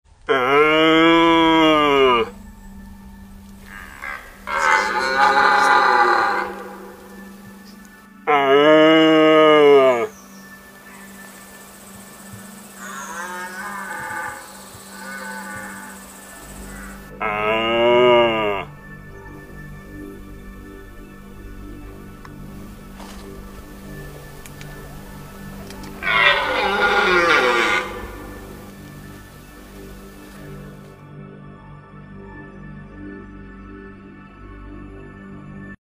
Ryk byka jelenia szlachetnego niosący się po Kniei to dźwięk przyprawiający o gęsią skórkę każdego myśliwego i przyrodnika.
Z wabikiem Clausen Red Stag Call możesz imitować ryk byka.
RedStagsCalling.mp4